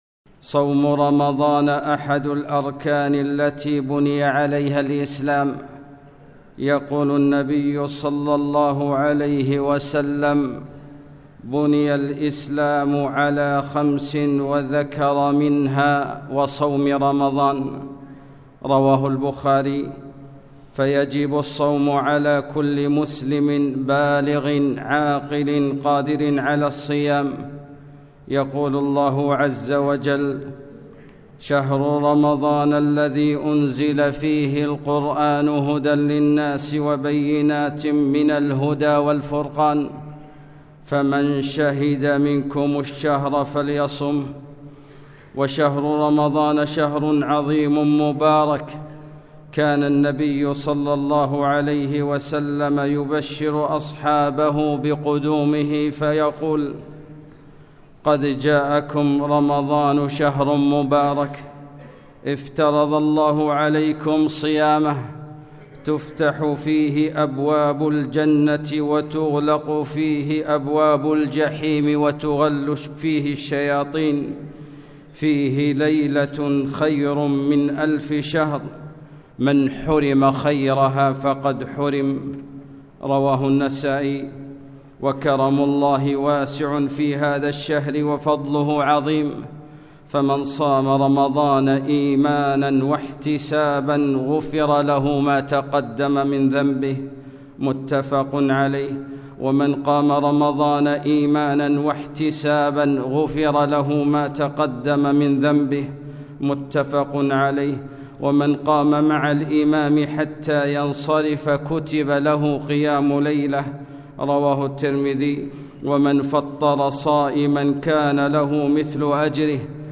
خطبة - أظلكم شهر عظيم - دروس الكويت